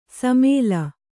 ♪ samēla